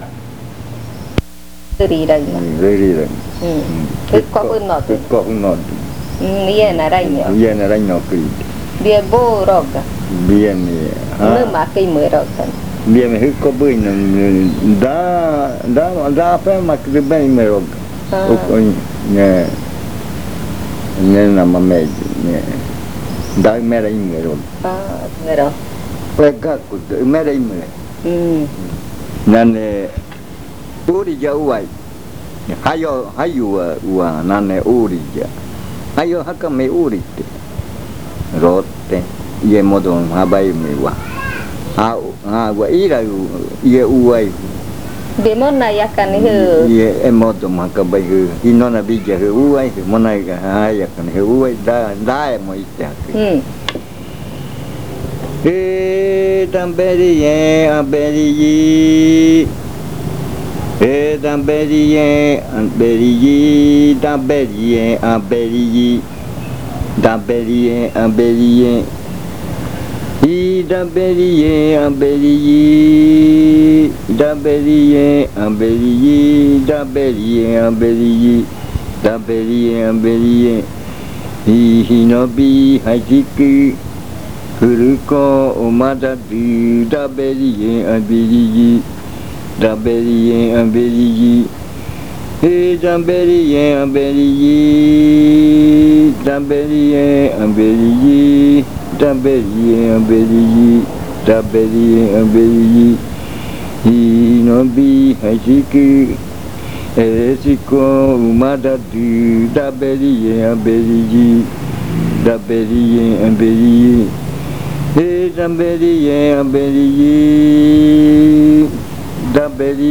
Leticia, Amazonas
Canto hablado (uuriya rua).
Este canto hace parte de la colección de cantos del ritual Yuakɨ Murui-Muina (ritual de frutas) del pueblo Murui
Spoken chant (uuriya rua).
This chant is part of the collection of chants from the Yuakɨ Murui-Muina (fruit ritual) of the Murui people